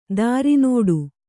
♪ dāri nōḍu